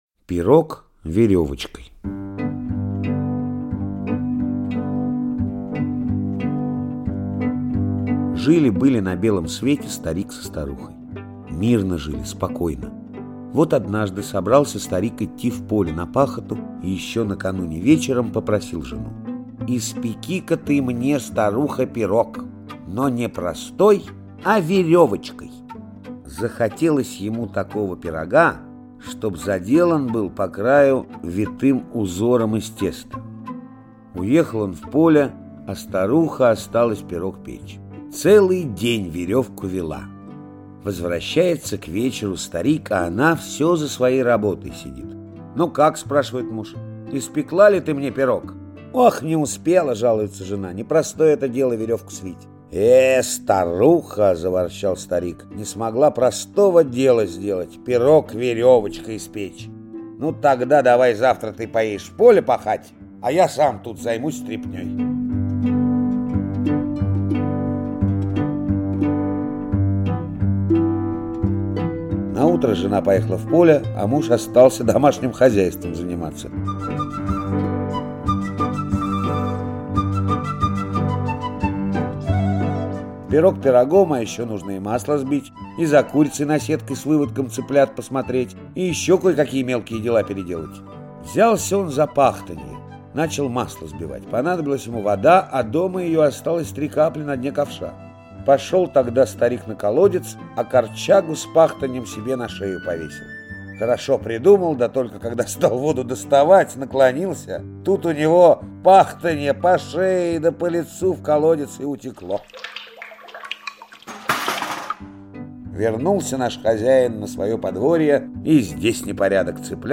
Пирог веревочкой - чувашская аудиосказка - слушать